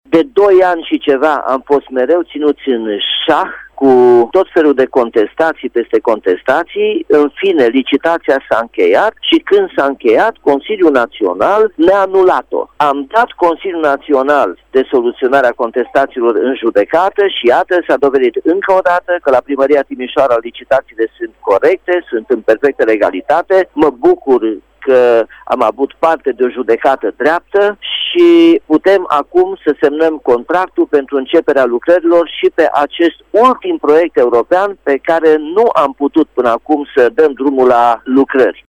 Este vorba de managamentul computerizat al traficului, proiect prin care se asigură undă verde la semafoarele din Timişoara, spune primarul Nicolae Robu.